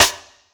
Snare MadFlavor 8.wav